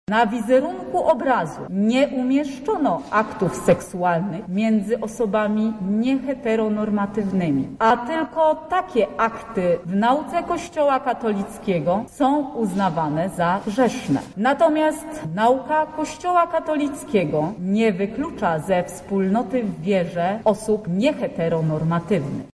• czytała sędzia Agnieszka Warchoł podczas uzasadnienia.